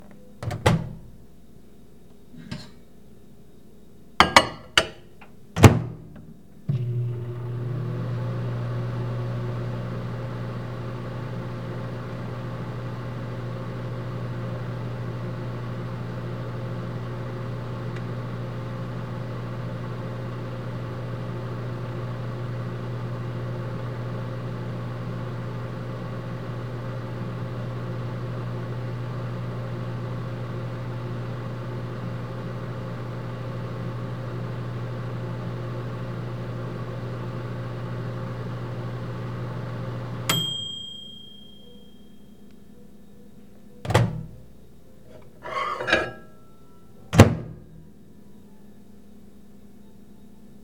microwave
appliance bell ding drone kitchen life lunch machine sound effect free sound royalty free Nature